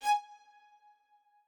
strings3_20.ogg